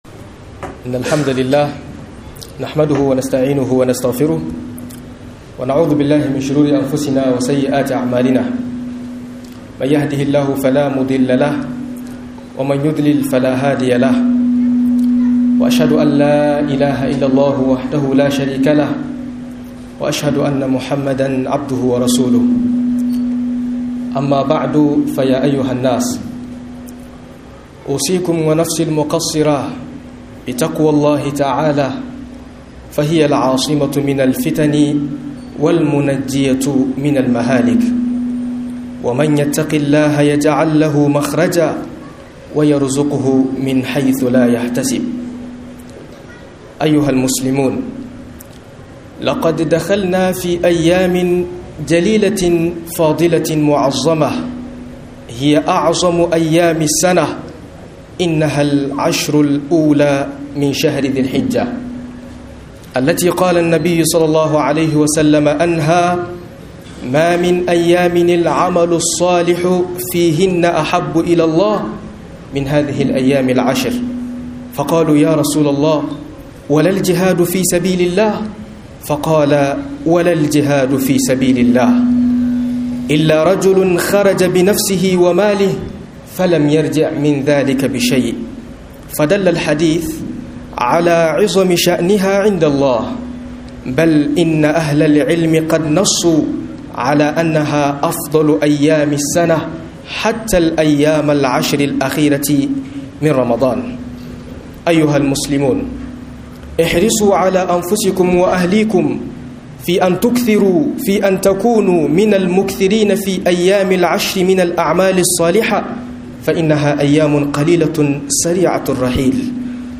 Ayukan Goman Zul hijja - MUHADARA